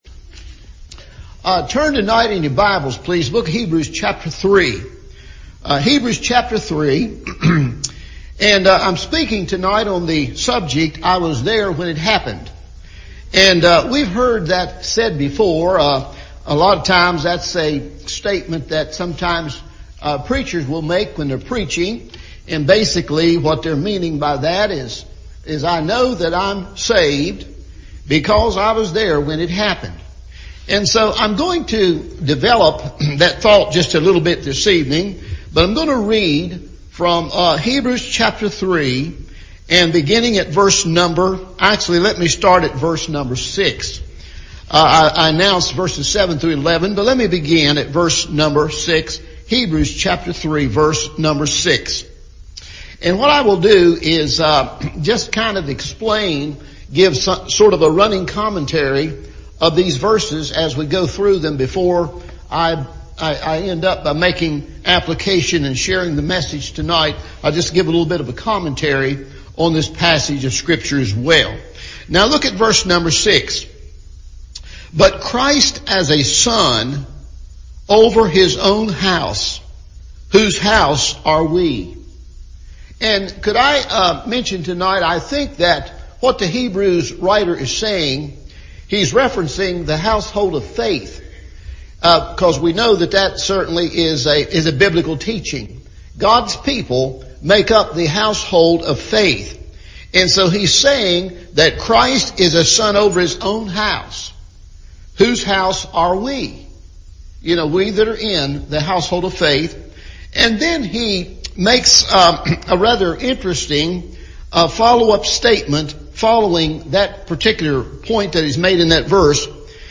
I Was There When It Happened – Evening Service